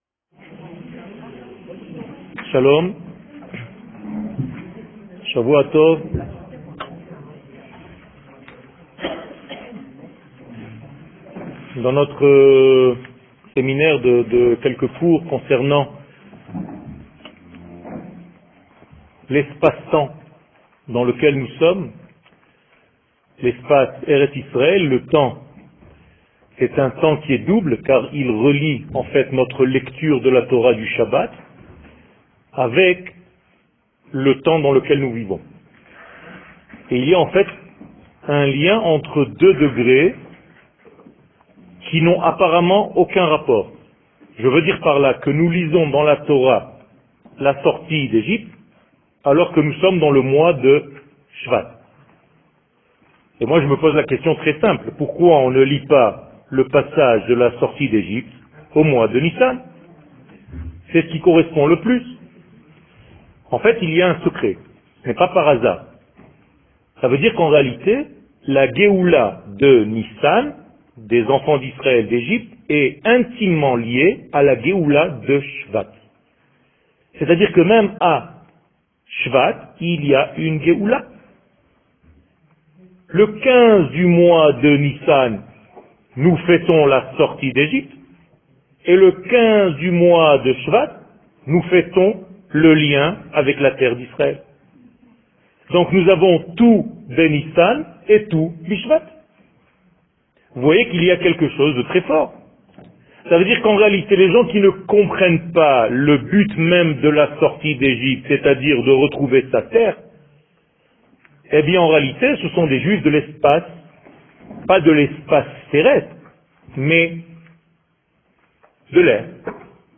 Sortir d'Egypte Paracha שיעור מ 13 ינואר 2019 54MIN הורדה בקובץ אודיו MP3 (9.31 Mo) הורדה בקובץ אודיו M4A (6.46 Mo) TAGS : Pessah Etude sur la Gueoula Parasha Torah et identite d'Israel שיעורים קצרים